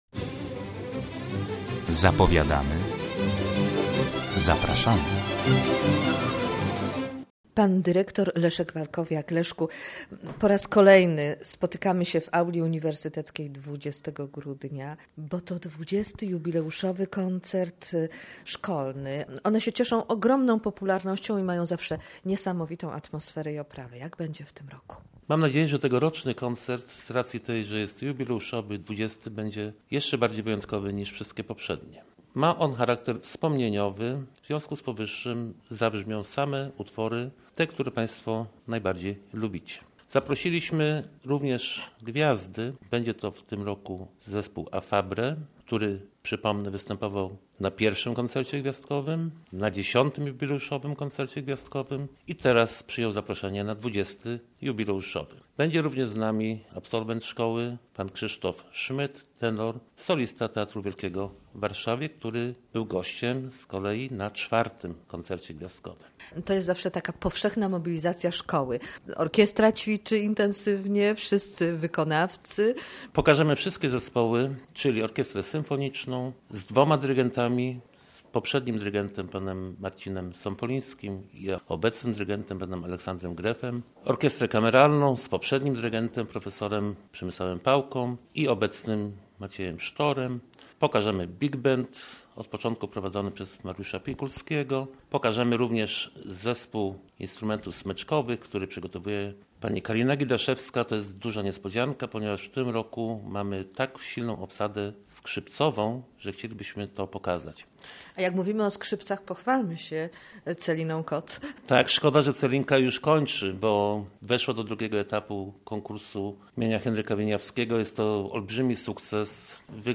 Od 20 lat Poznańska Ogólnokształcąca Szkoła Muzyczna II stopnia im. Mieczysława Karłowicza organizuje Koncerty Gwiazdkowe, które odbywają się zawsze 20 grudnia w Auli Uniwersyteckiej.